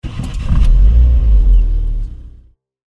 engine.mp3